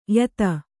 ♪ yata